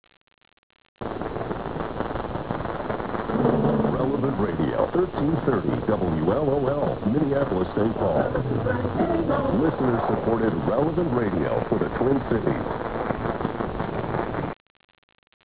This page contains DX Clips from the 2005 DX season!